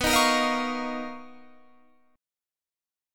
BM#11 chord